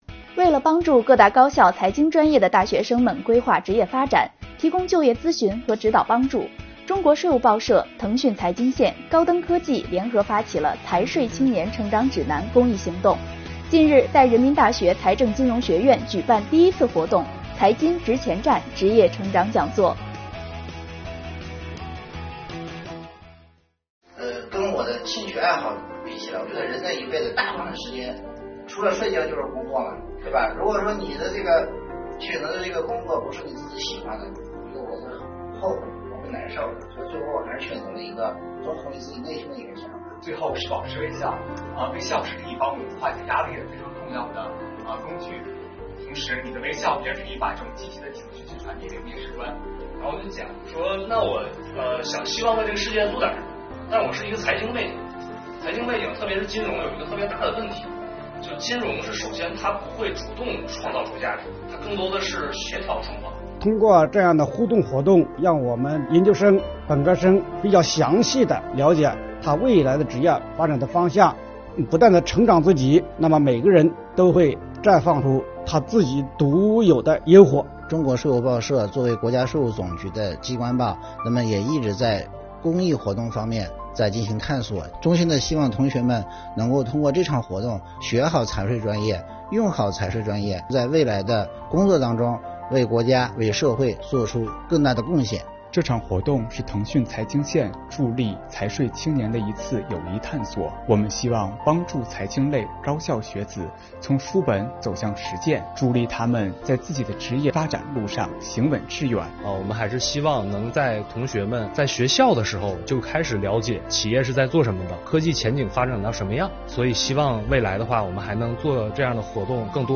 为了帮助各大高校财经专业的大学生们规划职业发展，提供就业咨询和指导帮助，中国税务报社、腾讯财经线、高灯科技联合发起了“财税青年成长指南”公益行动。近日，公益行动在中国人民大学财政金融学院举办了第一次活动——“财金职前站”职业成长讲座。快跟随镜头，一起走进中国人民大学讲座现场看看吧！